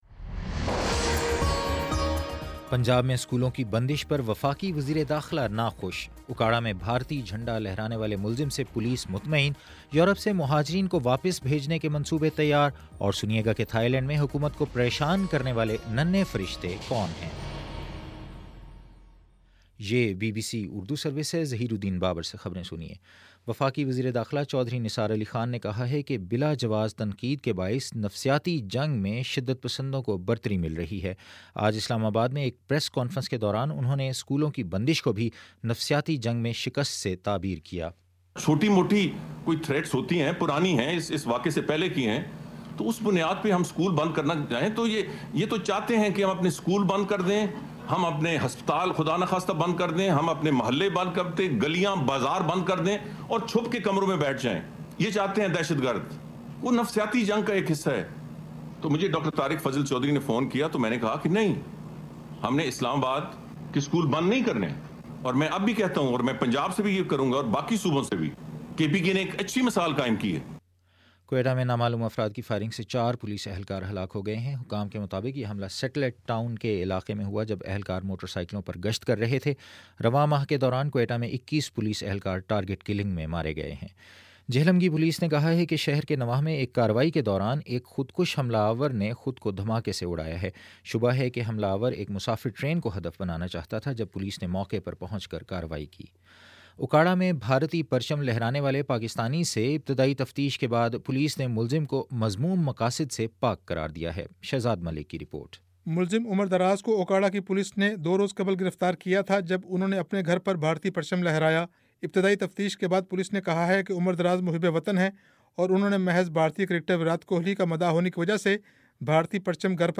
جنوری 28: شام سات بجے کا نیوز بُلیٹن